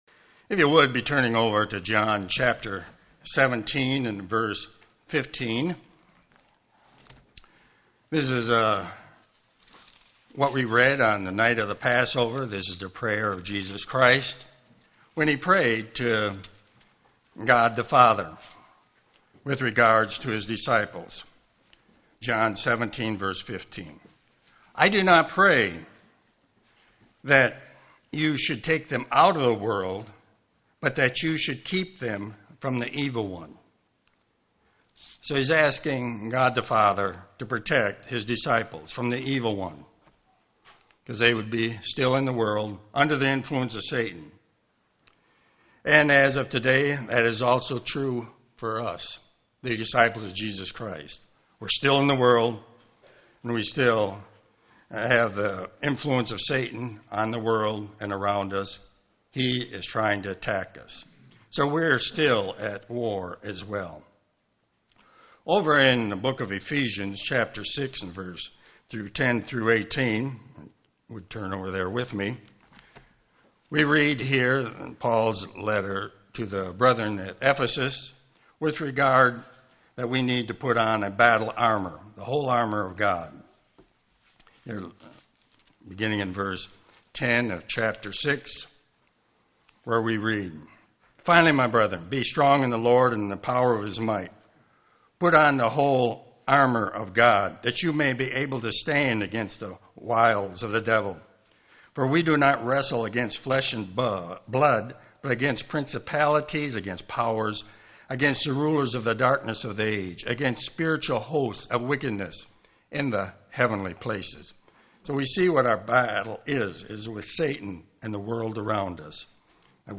Given in Ann Arbor, MI
UCG Sermon Studying the bible?